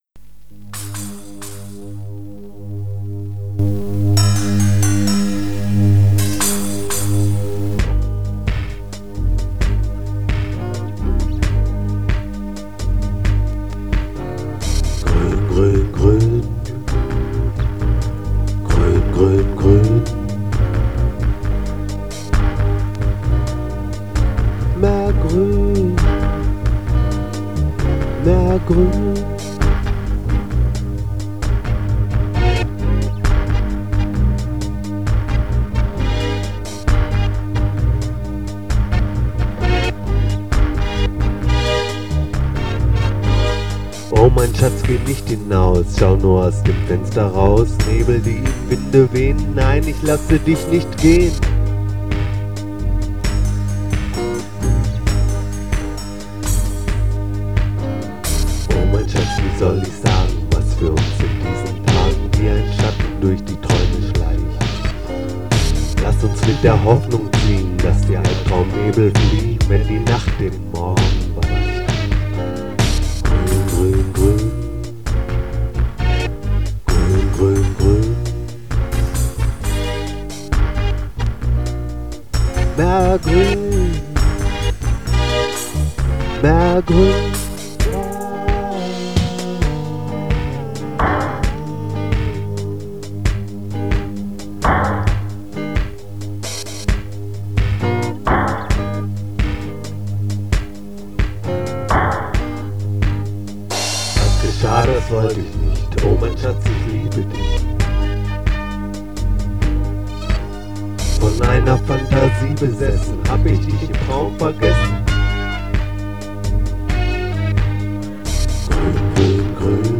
Gitarre und Vocal
Sologitarre
Bass
Drums